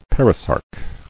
[per·i·sarc]